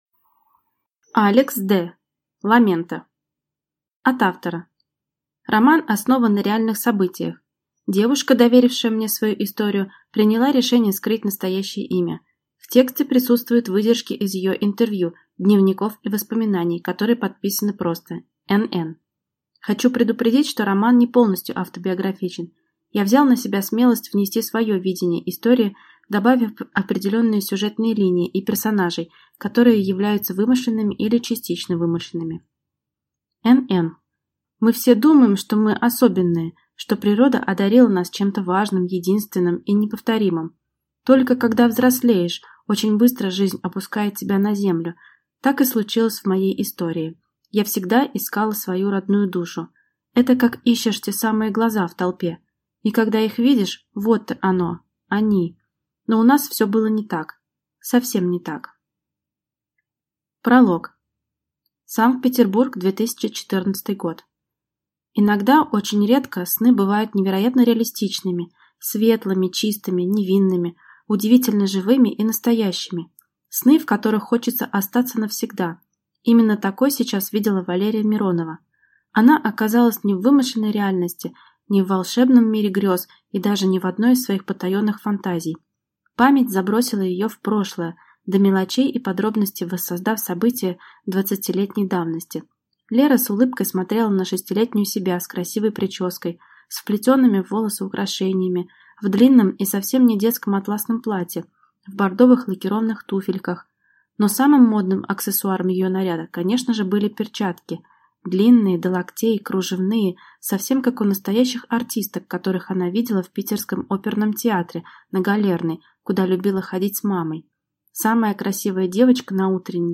Аудиокнига Ламенто | Библиотека аудиокниг